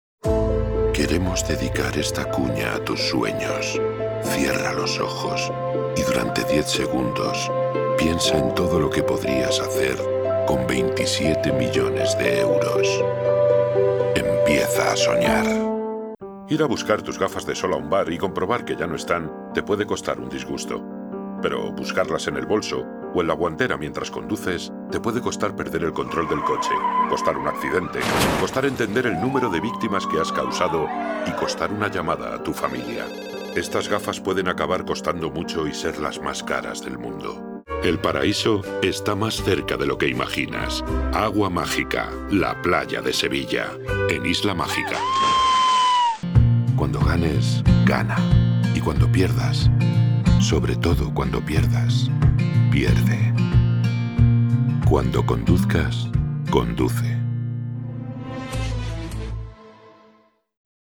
Ich mache auch Voiceovers für Werbung, Videos usw.
Konversation
Dynamisch